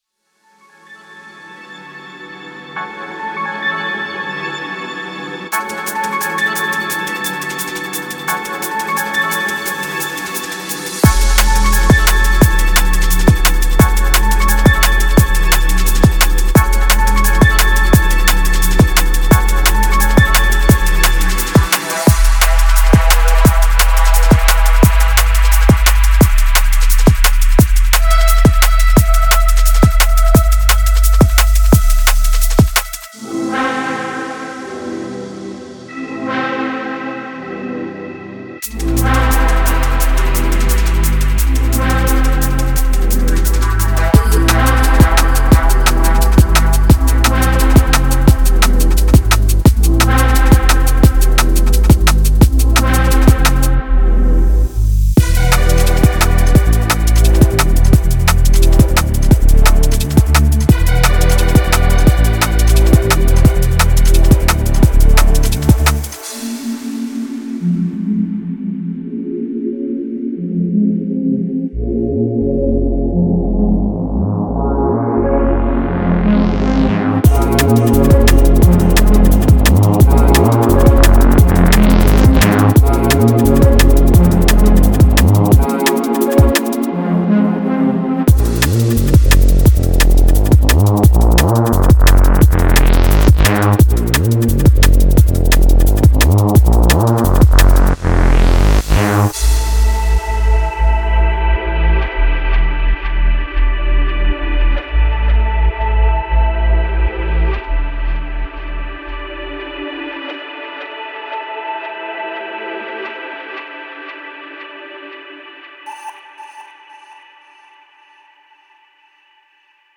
漂うようなパッドと空間的なテクスチャーがキャンバスを提供します。
シャープで推進力があり、自然にグルーヴを生み出すドラム。
ローリングブレイクは催眠的なパターンにロックし、深みとディテールを保ちながら動きを促します。
厚みのあるReeseラインはサブソニックな重みで震え、空気と脈動を同時に揺らします。
デモサウンドはコチラ↓
Genre:Drum and Bass
7 Atmosphere Loops
20 Bass Loops
20 Drum Loops